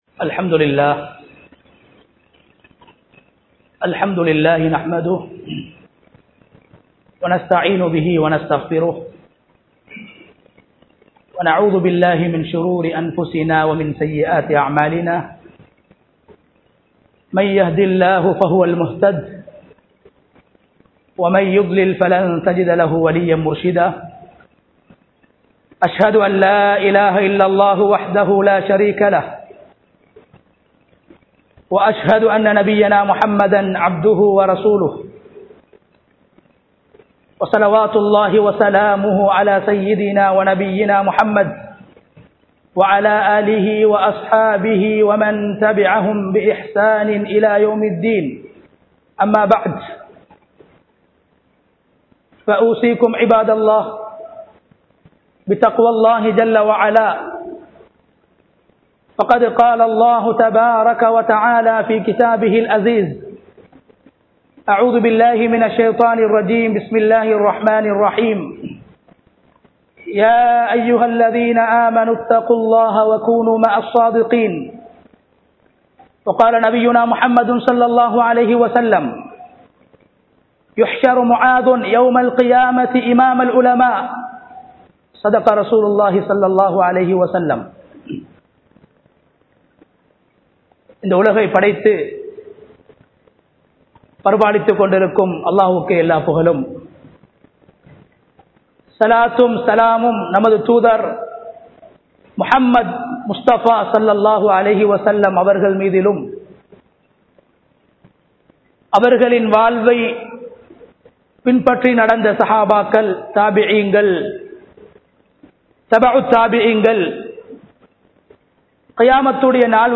முஆத் இப்னு ஜபல்(ரழி) | Audio Bayans | All Ceylon Muslim Youth Community | Addalaichenai
Kochikada Jumua Masjidh